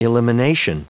Prononciation du mot elimination en anglais (fichier audio)
Prononciation du mot : elimination